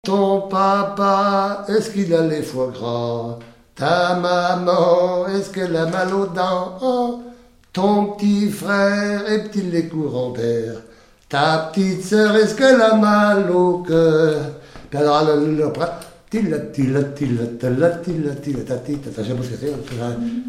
Polka
danse : polka
Pièce musicale inédite